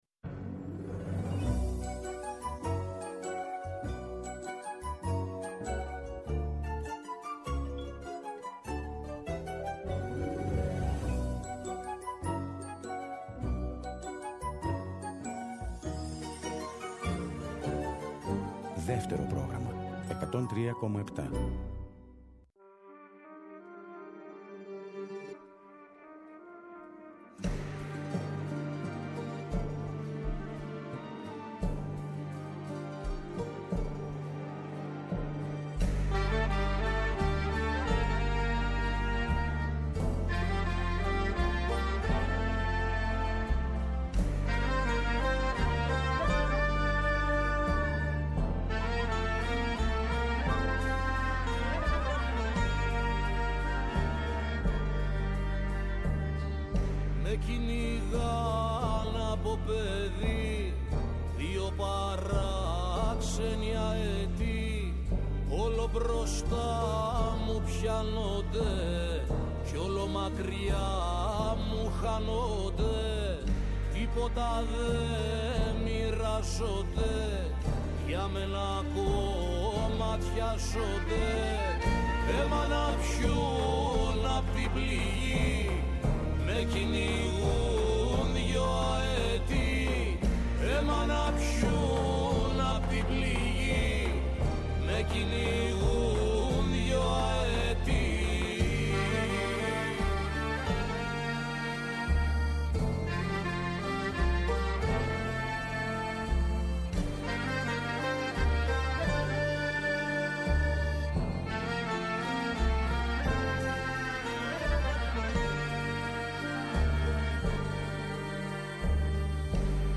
δύο κιθάρες και μια λύρα
Live στο Studio